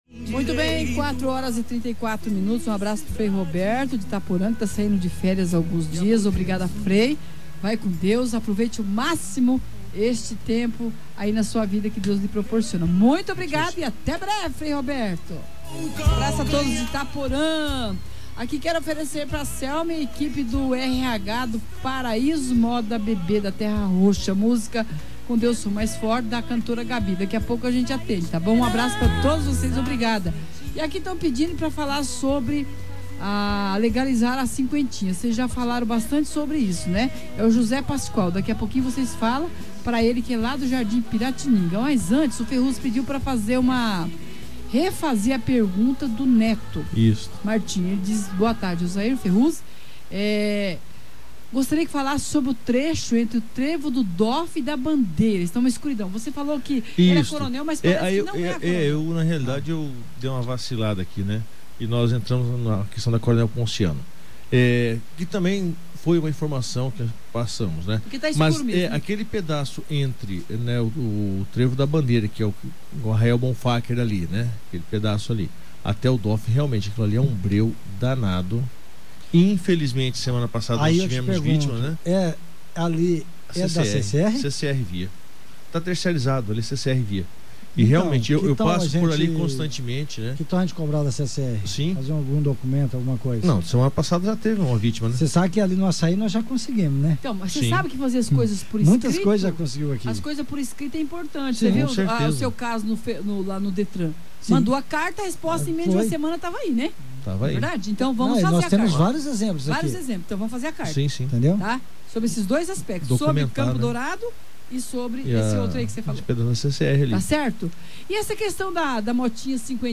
O Quadro Paz no Trânsito desta quarta-feira (04) debateu o tema educação para o trânsito: dever e responsabilidade. O quadro vai ao ar no Programa Um Só Coração e procura esclarecer dúvidas e assuntos relacionados ao trânsito.